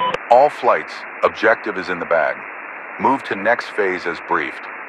Radio-commandObjectiveComplete2.ogg